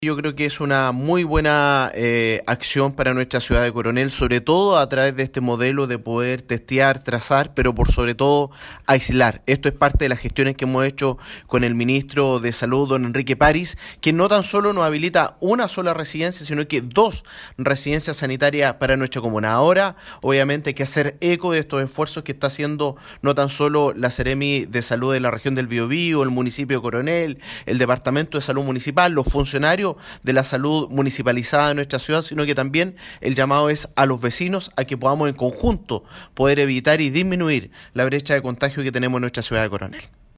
En este contexto, el alcalde de Coronel, Boris Chamorro, llamó a la ciudadanía a adoptar una conducta responsable que permita mantener controlados los contagios.
003-alcalde.mp3